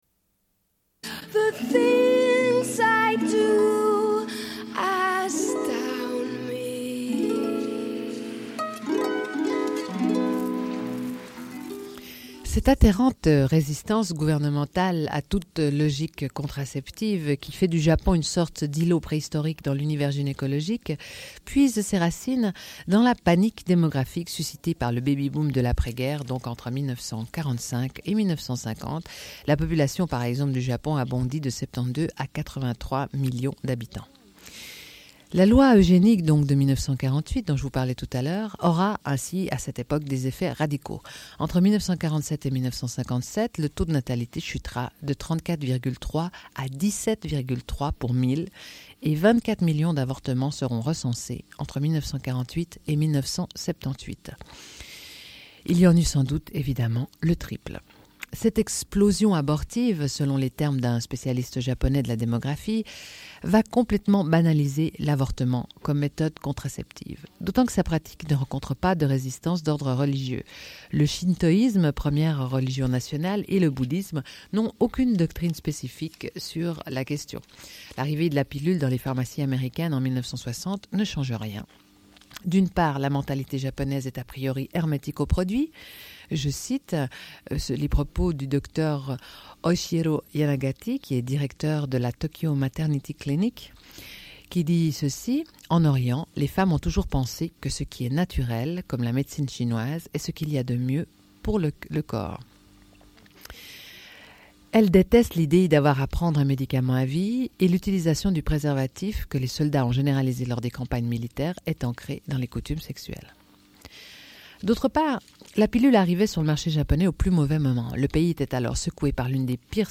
Une cassette audio, face B29:09